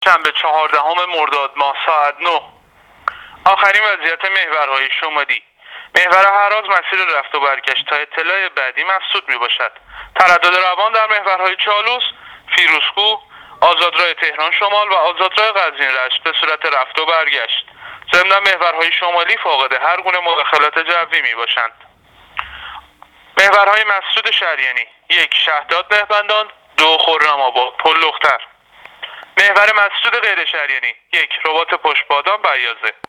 گزارش رادیو اینترنتی از آخرین وضعیت ترافیکی جاده‌ها تا ساعت ۹ چهاردهم مرداد